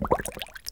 Index of /90_sSampleCDs/E-MU Producer Series Vol. 3 – Hollywood Sound Effects/Water/Bubbling&Streams